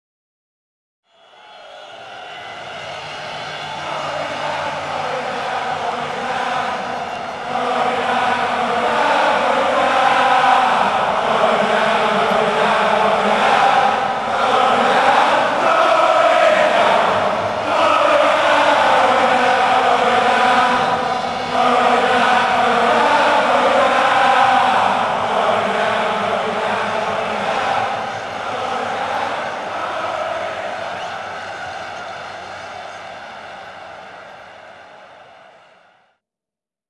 chants.big